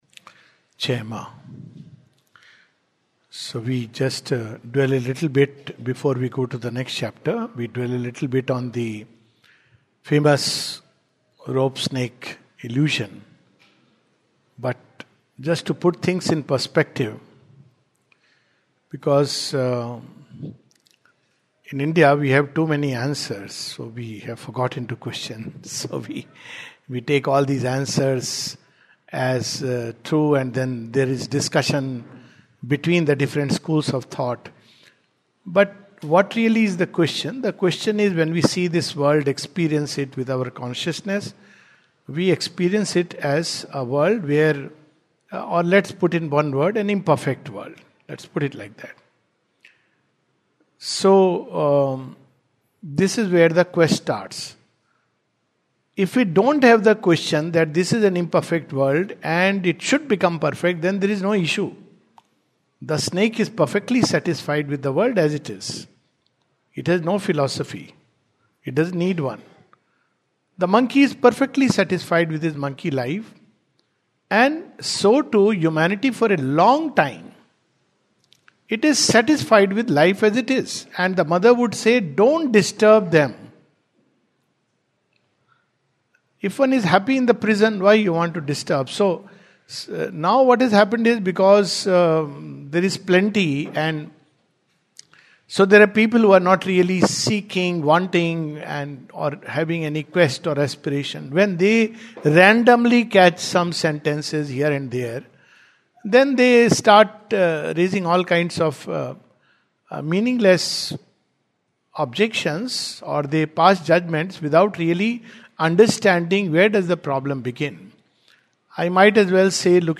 The Life Divine, 24th February 2026, Session # 06-05 at Sri Aurobindo Society, Pondicherry - 605002, India.